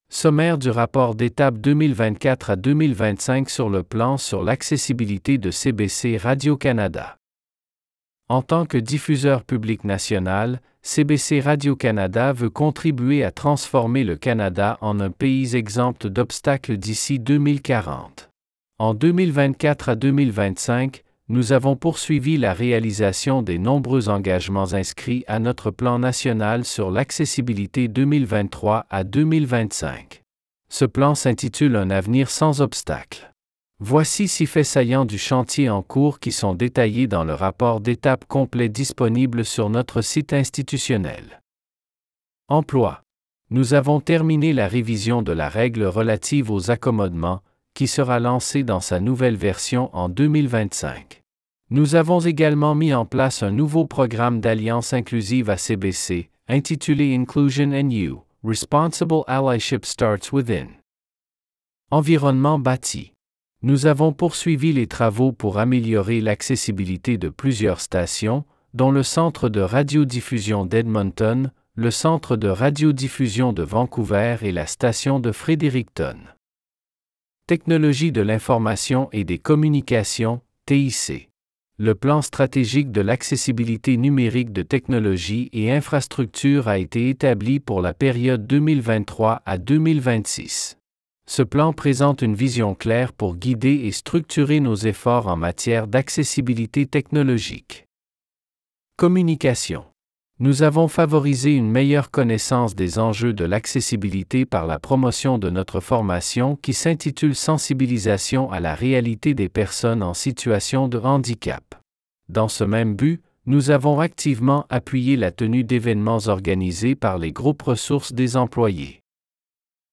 summary-progress-report-audio-ai-fr.wav